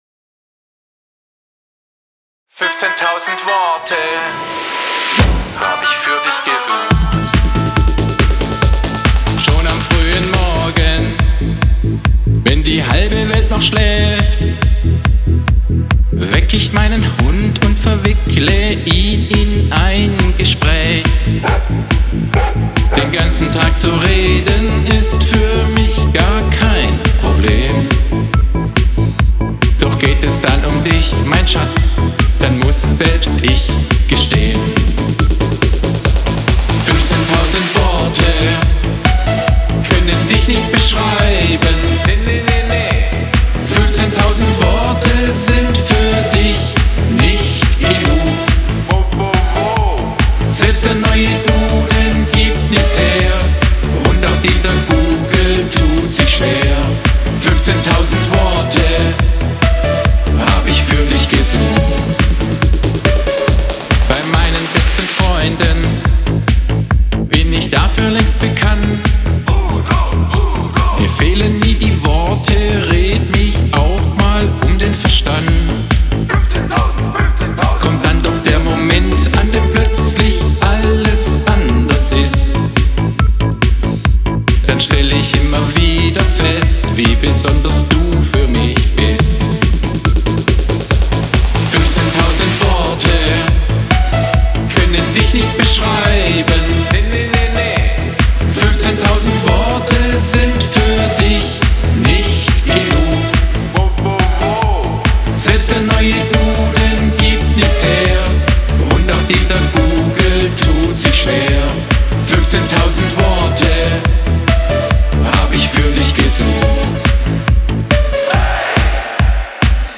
Ich habe mich mit Home-Recording und eigenen Songs beschäftigt.
Gesang